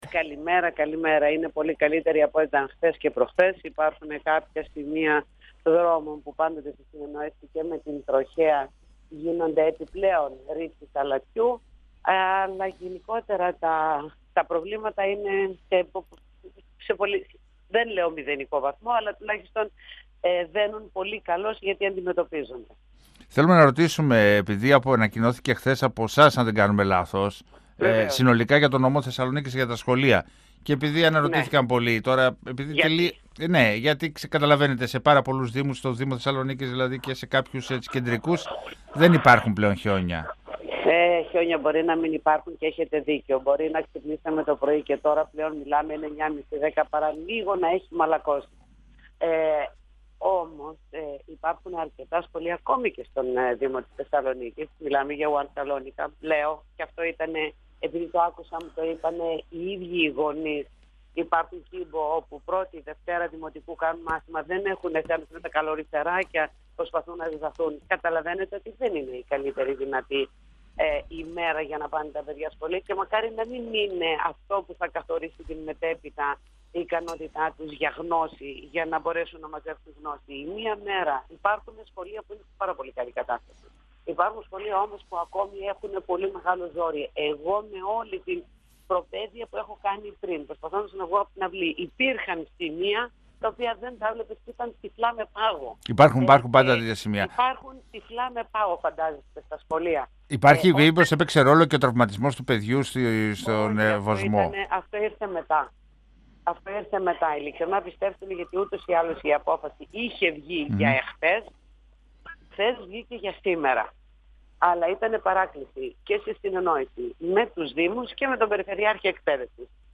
Η αντιπεριφερειάρχης Βούλα Πατουλίδου, στον 102FM του Ρ.Σ.Μ. της ΕΡΤ3
Για την αποφυγή τυχόν κινδύνου για τους μαθητές, αποφασίστηκε να μείνουν κλειστά τα σχολεία στο νομό Θεσσαλονίκης και την Παρασκευή, διευκρίνισε η αντιπεριφερειάρχης Βούλα Πατουλίδου μιλώντας στον 102FM του Ραδιοφωνικού Σταθμού Μακεδονίας της ΕΡΤ3 ενώ αναφέρθηκε και στη συνεργασία της Περιφέρειας Κεντρικής Μακεδονίας με τους δήμους.